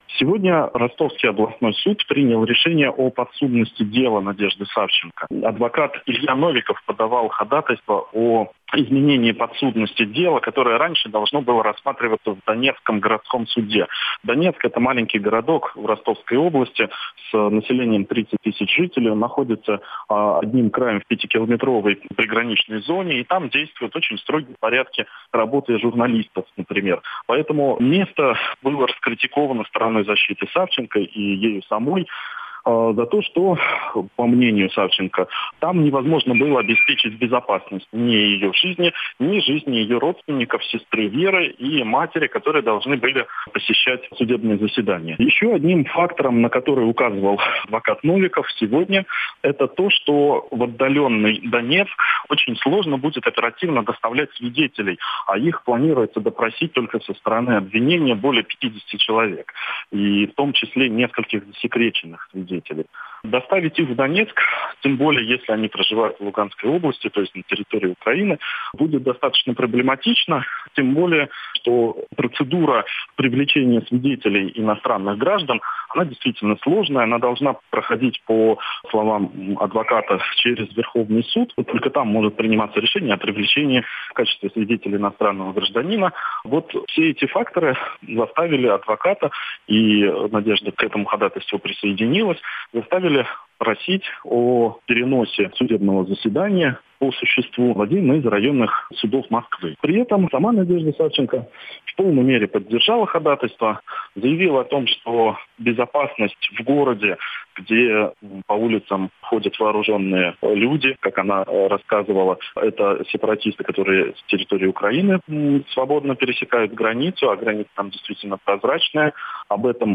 из зала суда